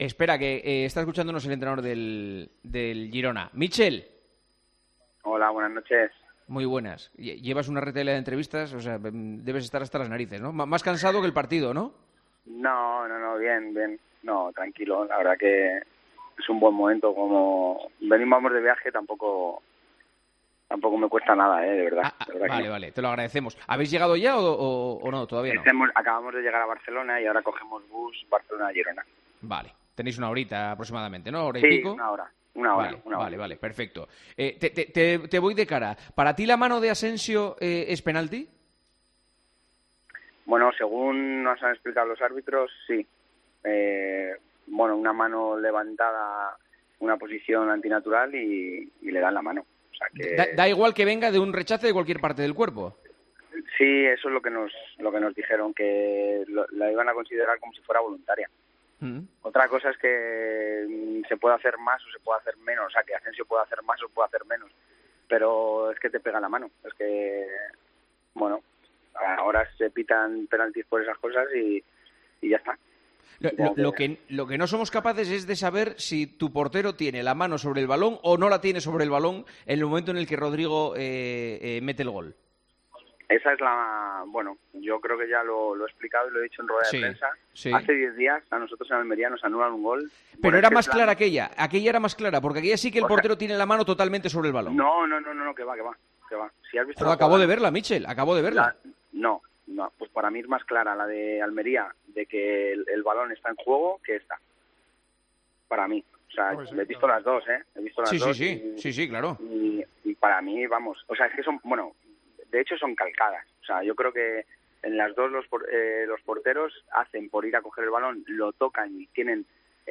Hablamos en Tiempo de Juego con el entrenador del Girona, Míchel, de la polémica del partido ante el Real Madrid en el que su equipo ha logrado un empate a uno gracias a un dudoso penalti pitado por mano de Asensio: "Según nos han dicho los árbitros la mano de Asensio es penalti y la van a considerar como si fuera voluntaria"